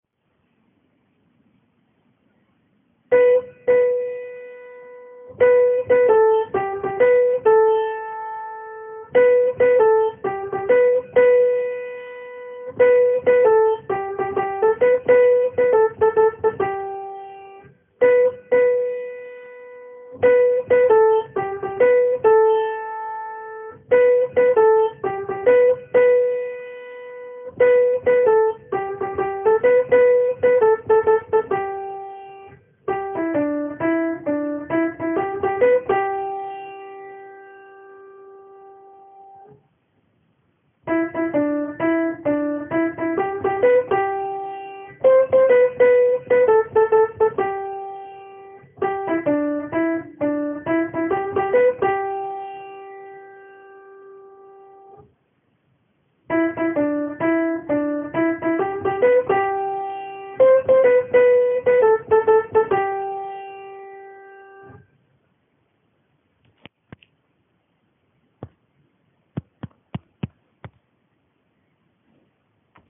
Good News- Sopranos
Good-News-Sopranos.mp3